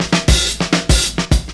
DRUMFILL03-R.wav